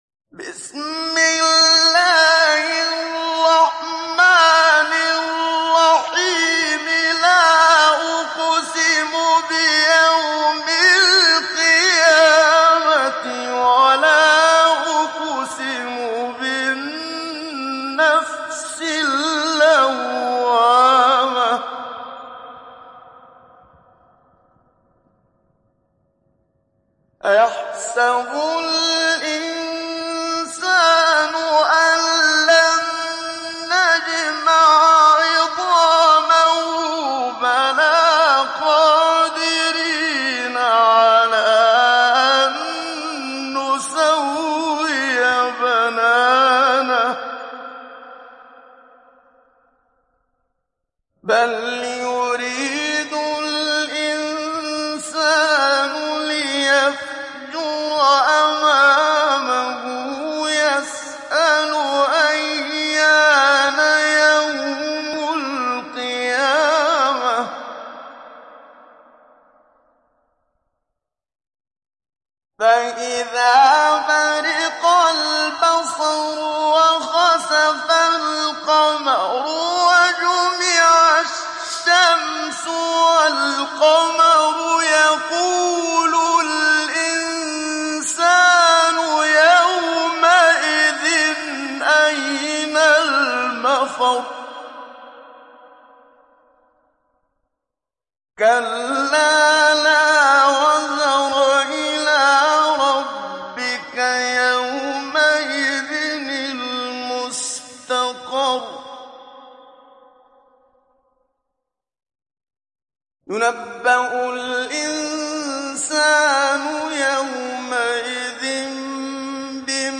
دانلود سوره القيامه محمد صديق المنشاوي مجود